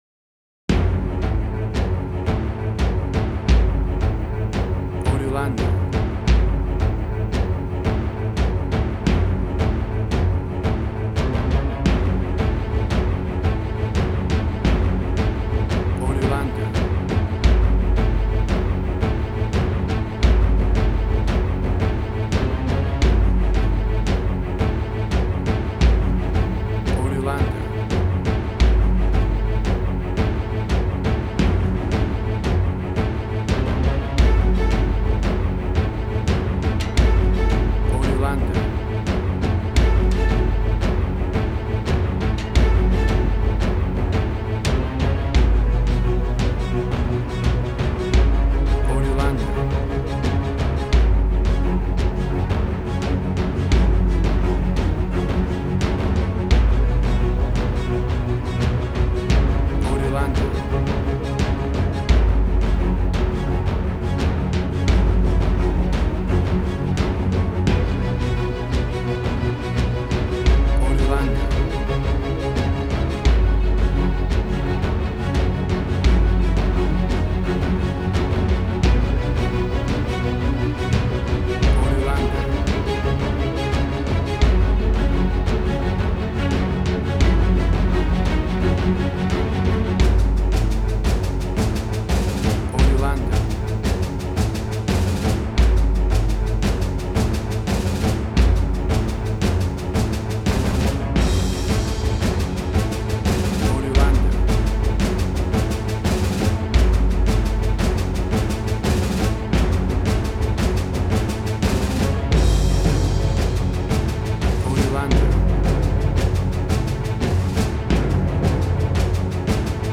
WAV Sample Rate: 16-Bit stereo, 44.1 kHz
Tempo (BPM): 87